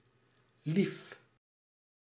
Ääntäminen
IPA: /nɛt/